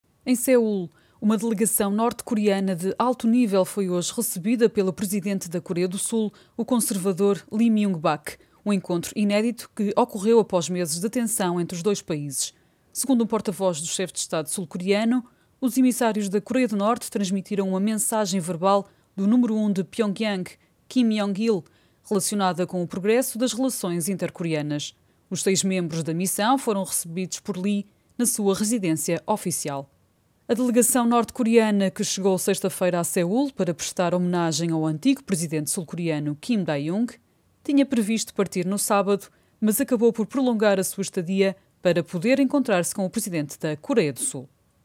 Portuguese native speaker and voice over talent.
Sprechprobe: Sonstiges (Muttersprache):